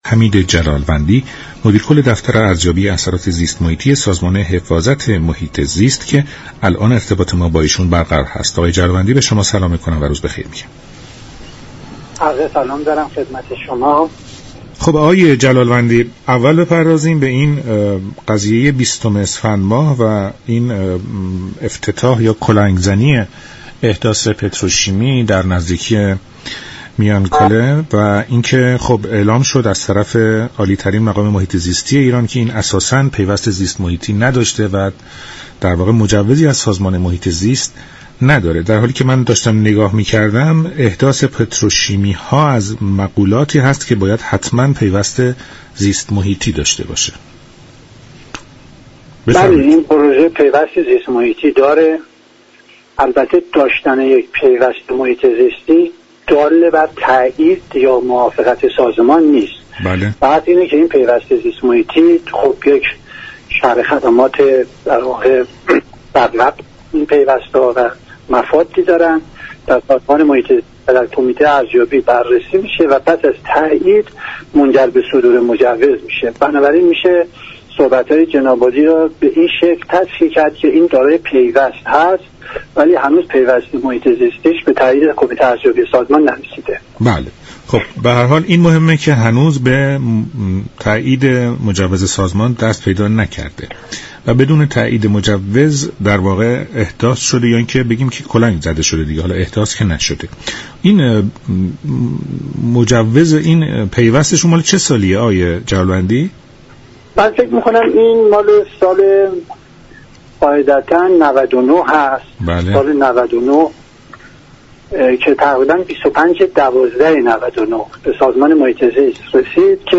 میان كاله تنها جزیره دریای خزر، یك ذخیره گاه زیست كره است در ادامه این گفت و گوی رادیویی، سمیه رفیعی رییس فراكسیون محیط زیست مجلس در برنامه «ایران امروز» در این باره گفت: وقتی صحبت از استقرار صنعتی خارج از گروه «الف» و «ب» می شود، یعنی می خواهیم كاری را خارج از قوانین انجام دهیم.